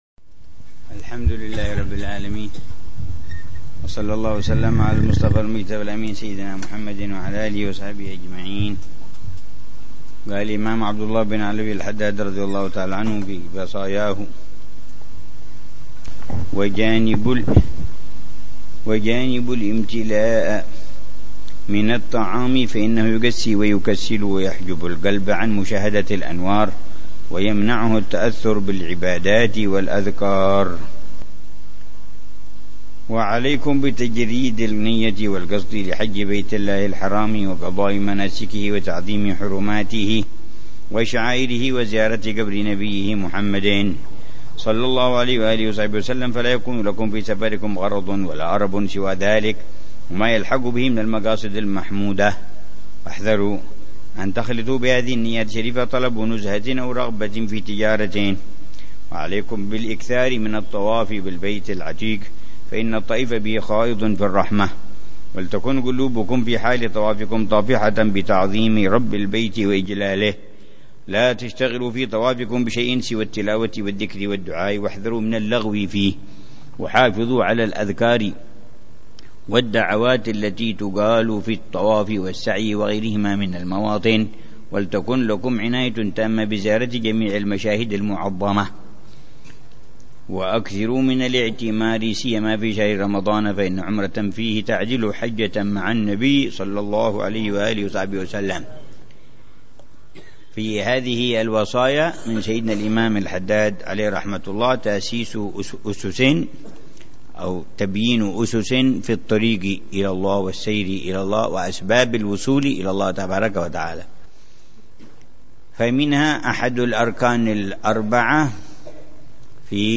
درس أسبوعي يلقيه الحبيب عمر بن حفيظ في كتاب الوصايا النافعة للإمام عبد الله بن علوي الحداد يتحدث عن مسائل مهمة في تزكية النفس وإصلاح القلب وطه